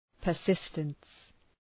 Προφορά
{pər’sıstəns}